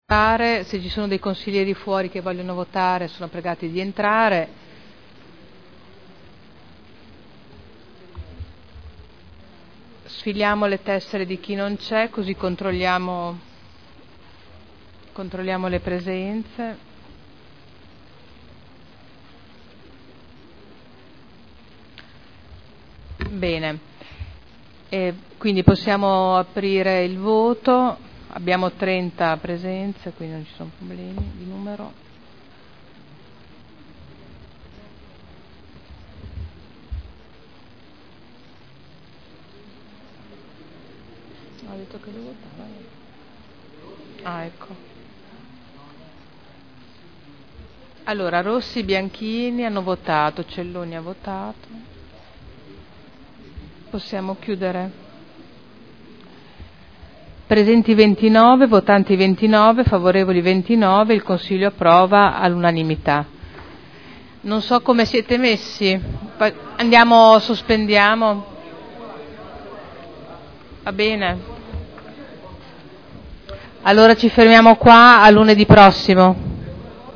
Seduta del 05/12/2011. Il Presidente Caterina Liotti metti ai voti.